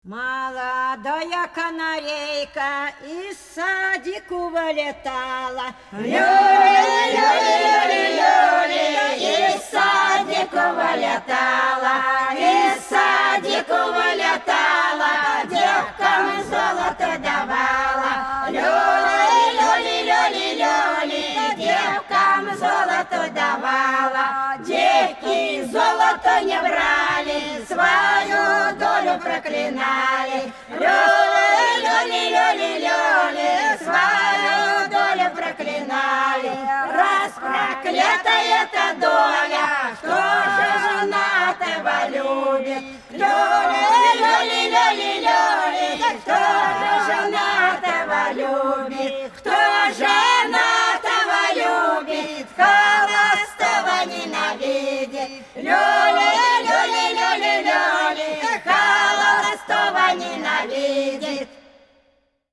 Пролетели все наши года Молодая канарейка – плясовая (Фольклорный ансамбль села Пчелиновка Воронежской области)
26_Молодая_канарейка_–_плясовая.mp3